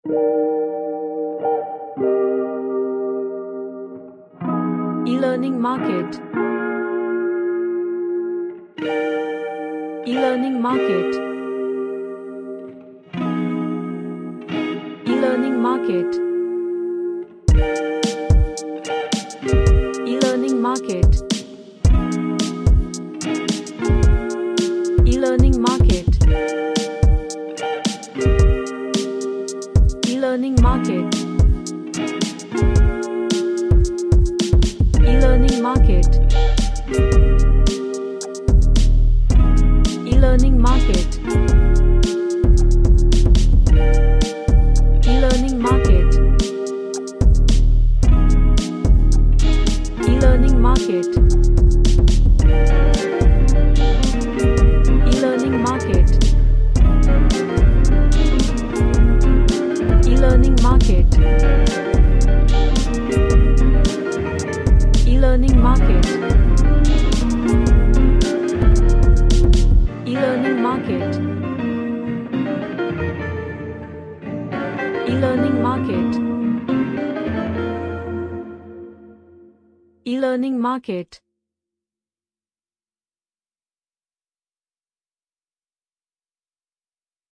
An ambient lofi track
Chill Out